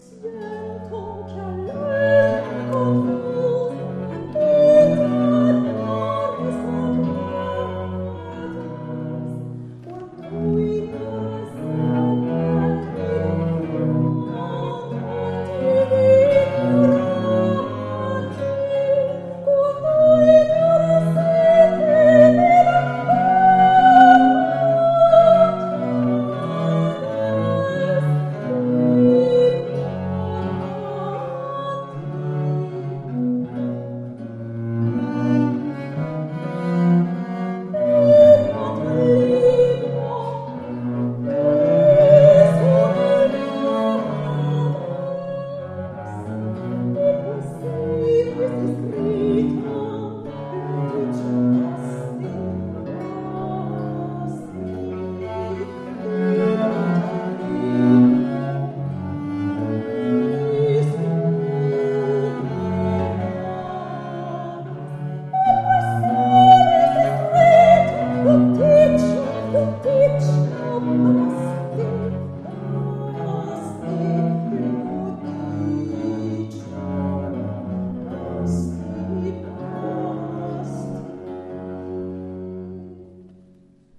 La musique baroque vivante !
Enregistré à l'église d'Arberats (64120) le 24 Juillet 2012